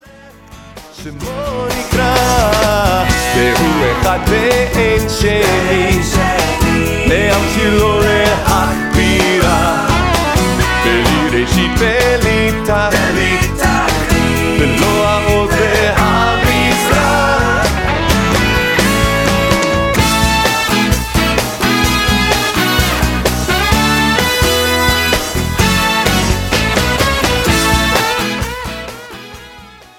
CJM (Contemporary Jewish Music)